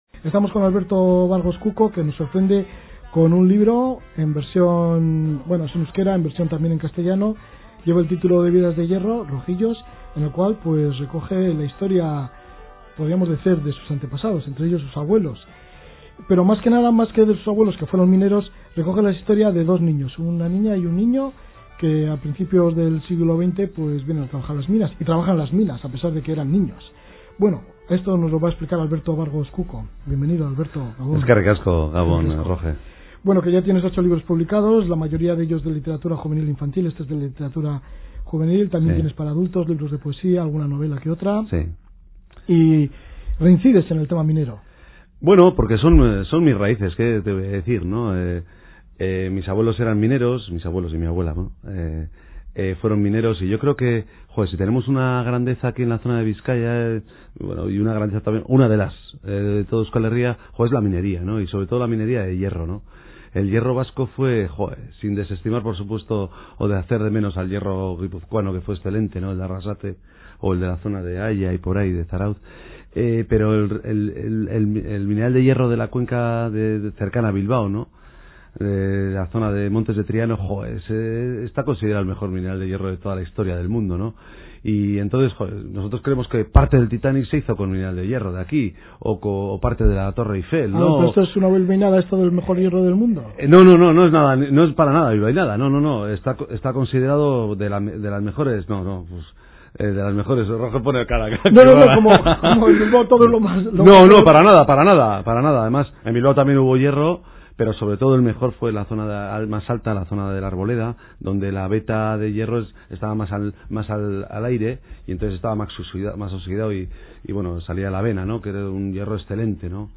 presentacion radio euskadi la casa de la palabra.mp3